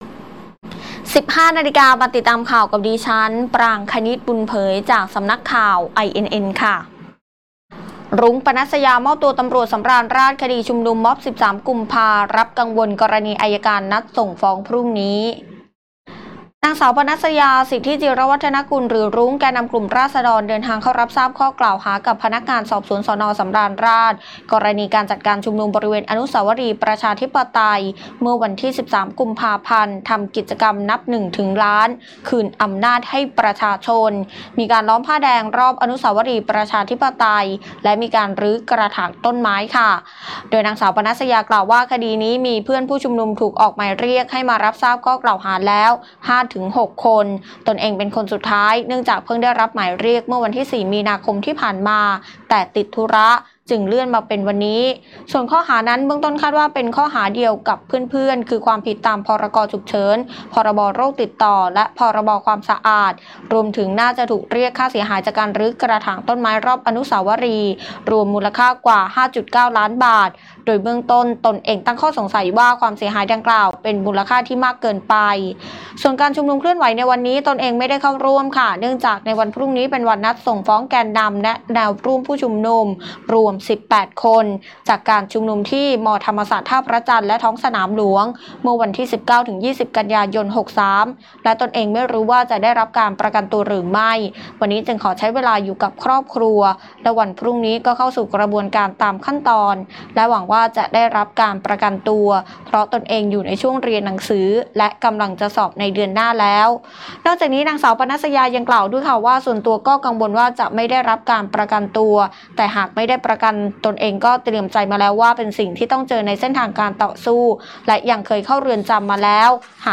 คลิปข่าวต้นชั่วโมง
ข่าวต้นชั่วโมง 15.00 น.